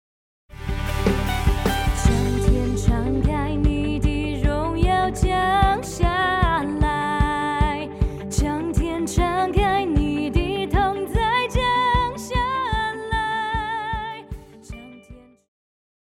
宗教
键盘
乐团
演奏曲
独奏与伴奏
有主奏
有节拍器